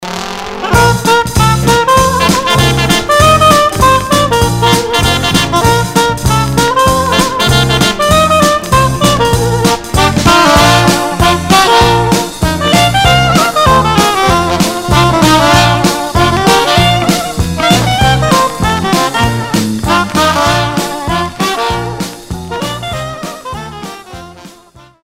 Versions "Jazz"